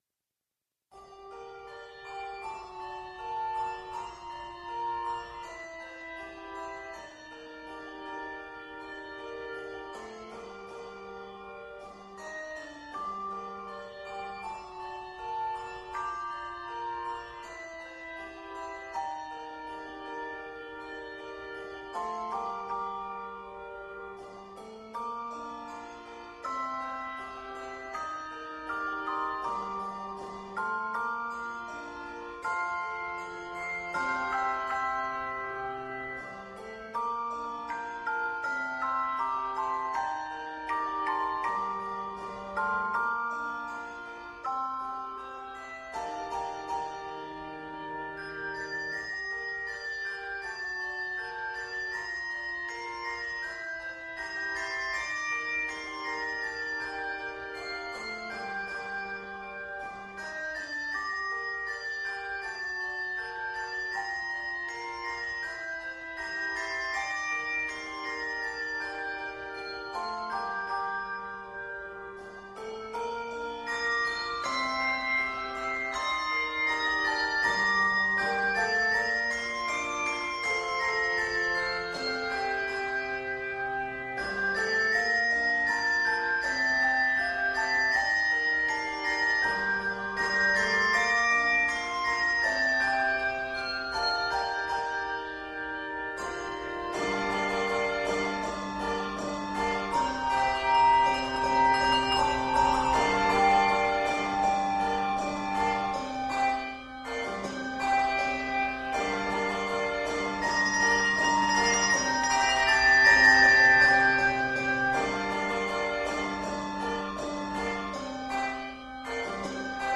Review: Handbells ROCK!